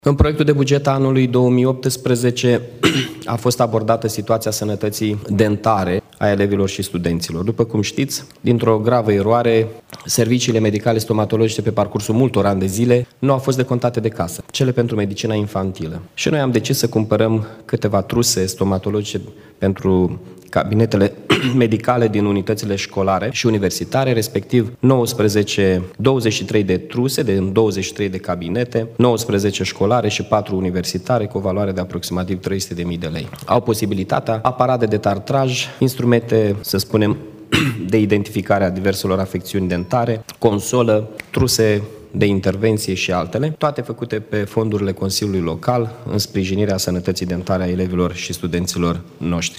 Primarul municipiului Iași, Mihai Chirica